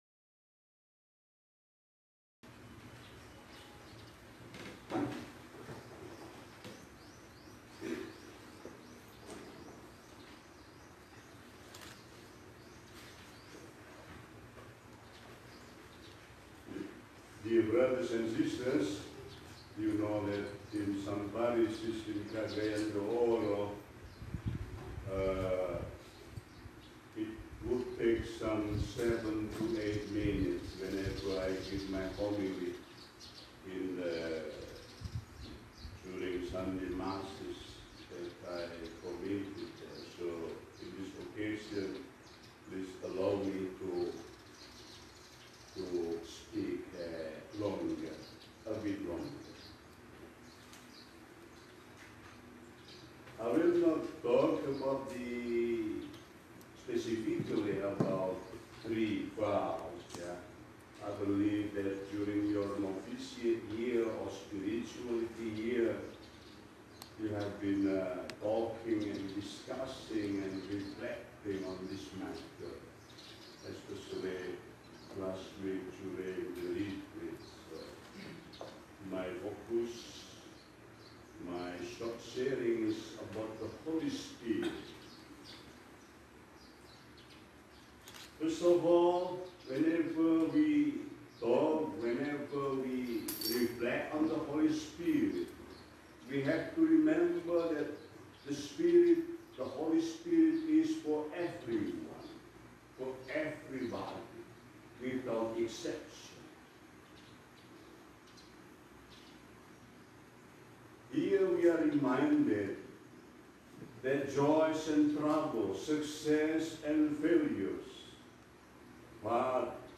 First Profession of Vows in the Novitiate Fr. Dehon in Lower Lucoban
homily